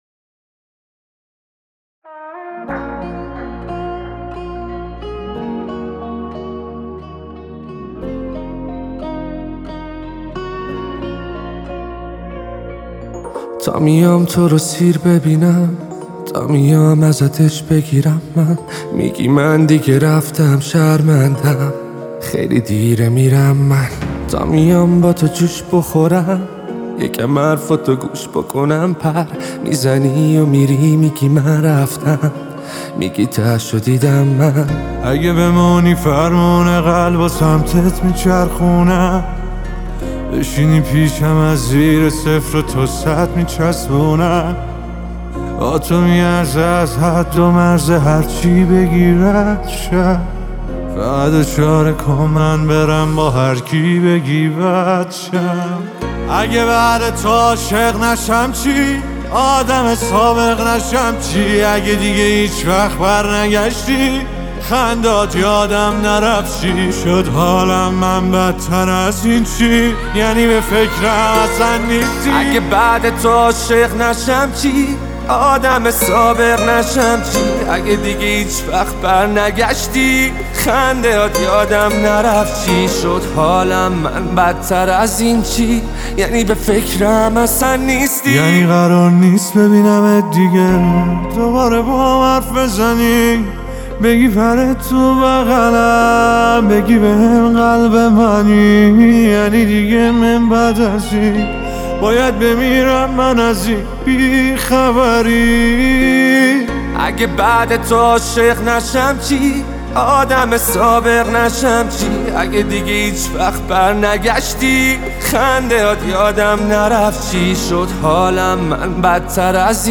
کاور